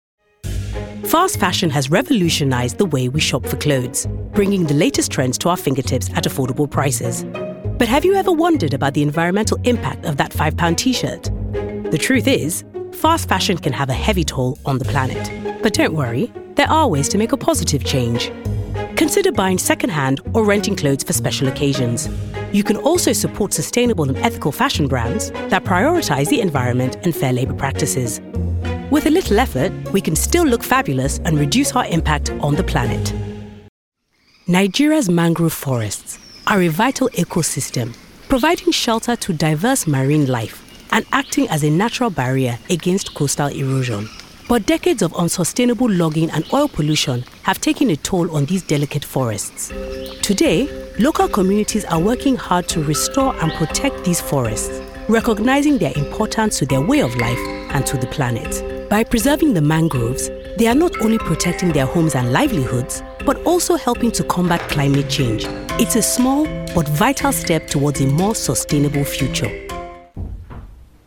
Female. African/Black British.
Narration